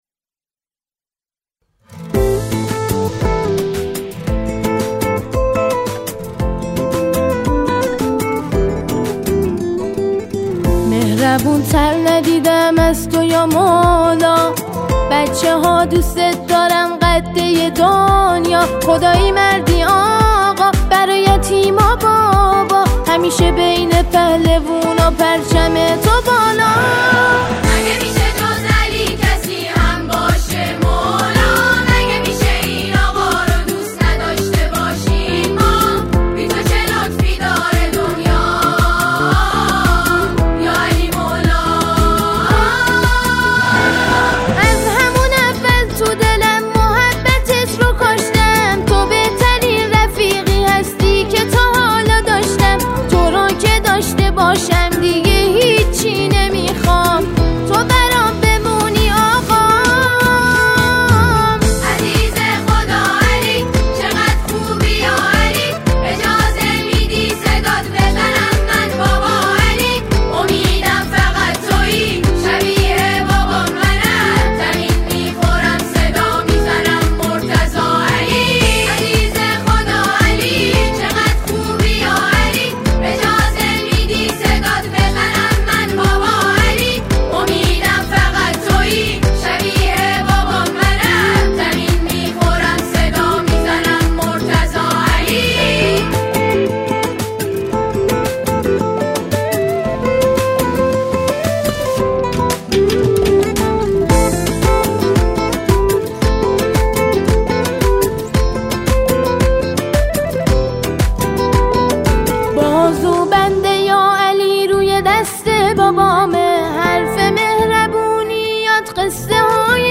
به صورت جمع خوانی به مرحله اجرا درآمده است